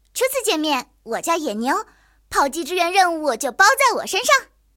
野牛登场语音.OGG